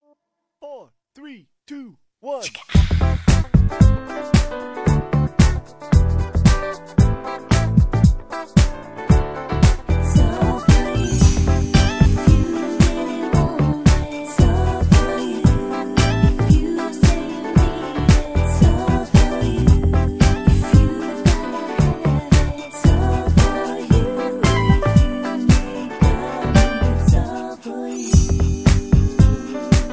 (Version-3, Key-G) Karaoke MP3 Backing Tracks
Just Plain & Simply "GREAT MUSIC" (No Lyrics).